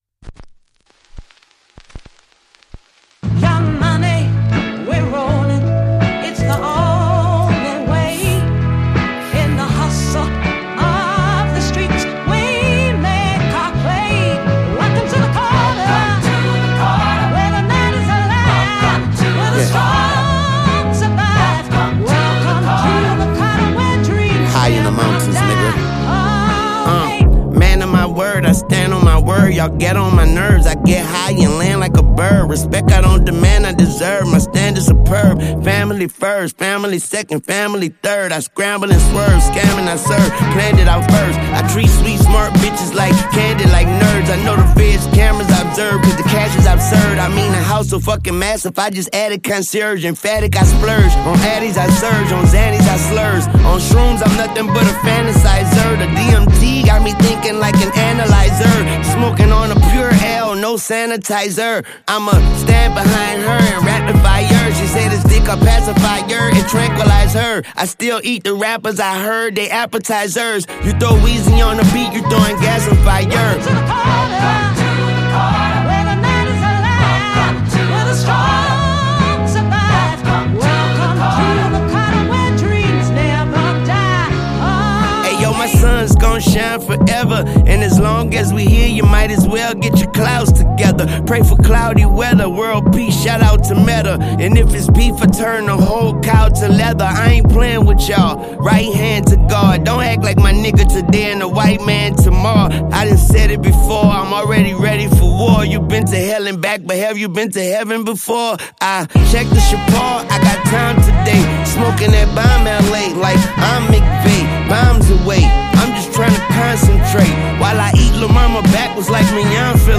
smooth beats
His flow is still creative and full of energy.